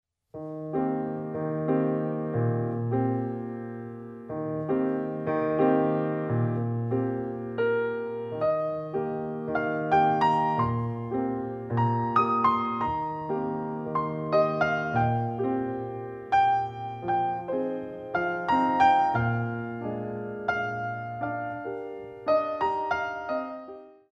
Rises On Pointes